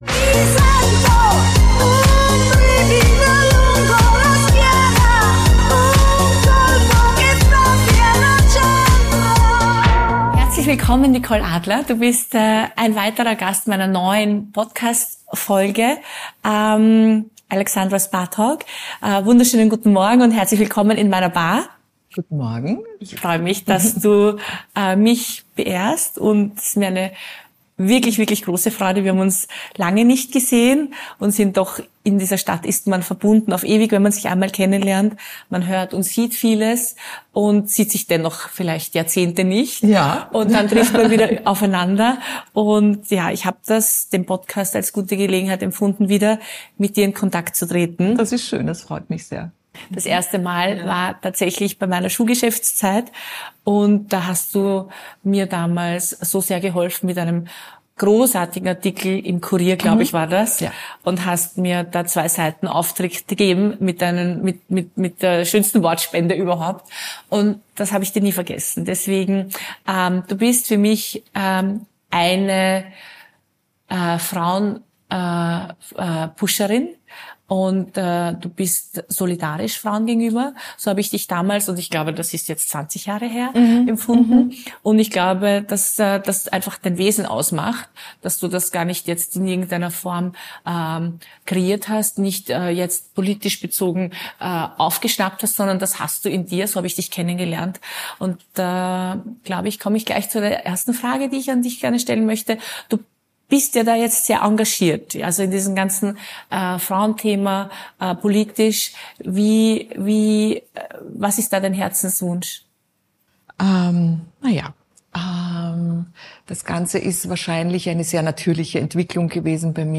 Zwischen Drink und Dialog entstehen Gespräche, die Tiefe haben - mal leise, mal laut, aber es immer wird Tacheles geredet.